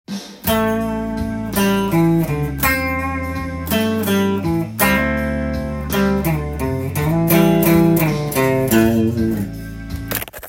②のような三連符を使ったリズムになります。
以下のようなギターソロになります。
基本リズム②とフレーズ②